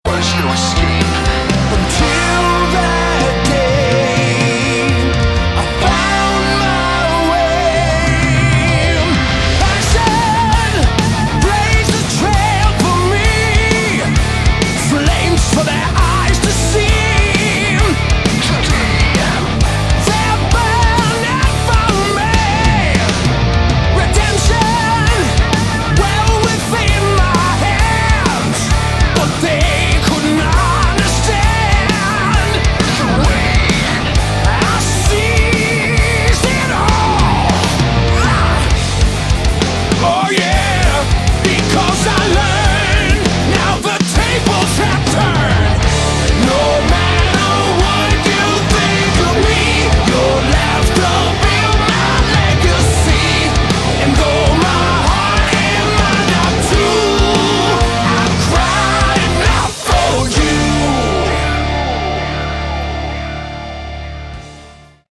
Category: Hard Rock
guitars
lead vocals
drums
bass
keyboards
backing vocals